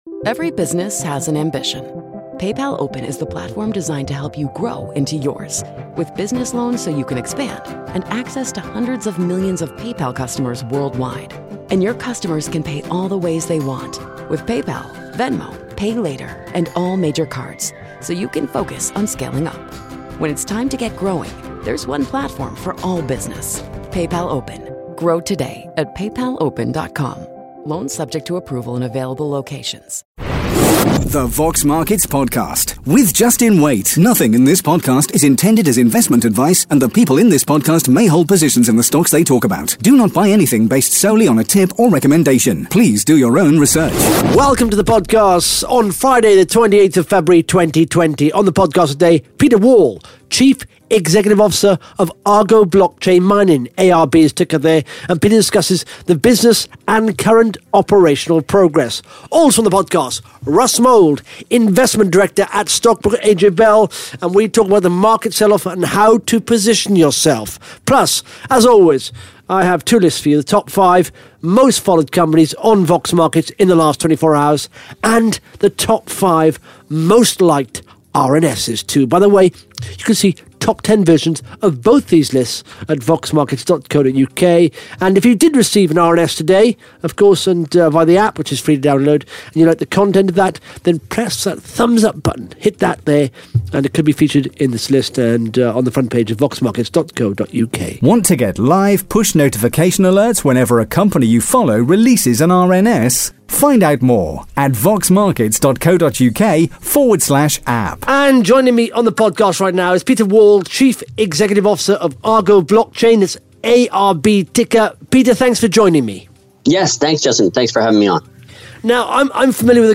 (Interview starts at 18 minutes 15 seconds) Plus the Top 5 Most Followed Companies & the Top 5 Most Liked RNS’s on Vox Markets in the last 24 hours.